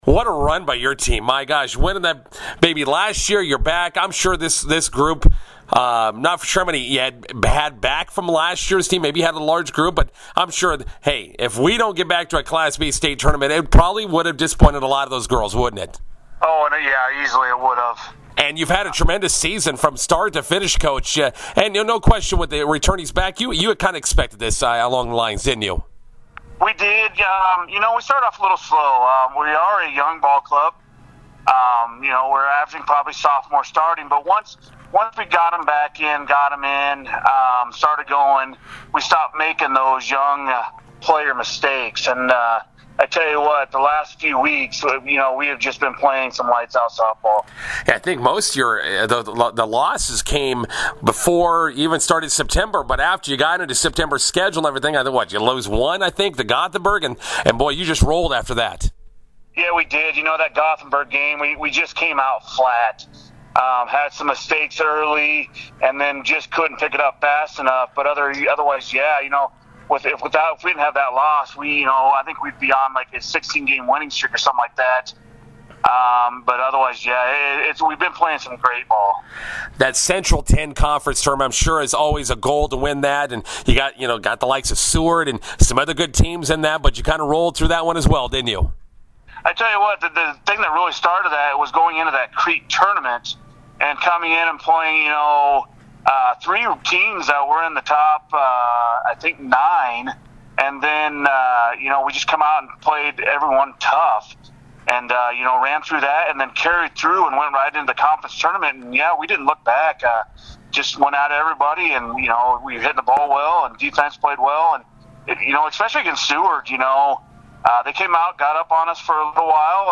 INTERVIEW: Class B defending softball champions hoping to punch state tournament ticket against McCook on Saturday.